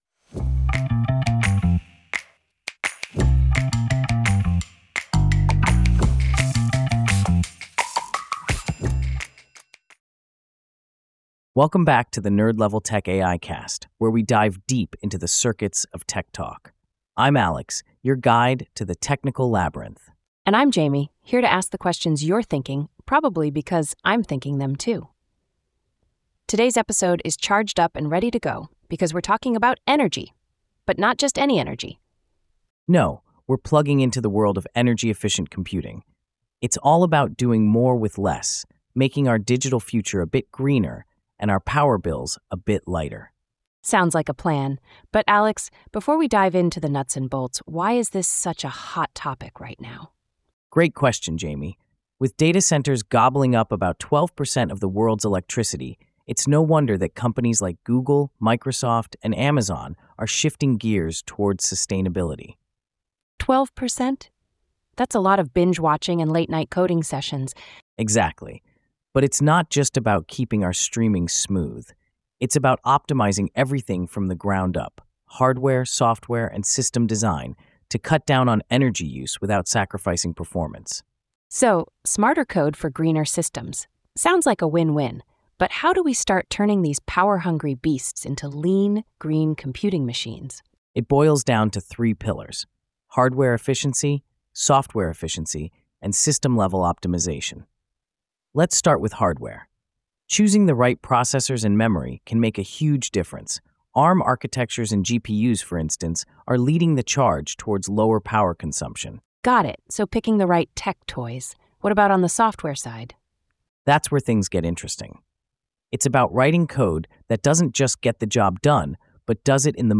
AI-generated discussion by Alex and Jamie